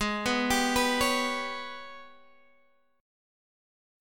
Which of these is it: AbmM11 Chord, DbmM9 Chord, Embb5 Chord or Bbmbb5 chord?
AbmM11 Chord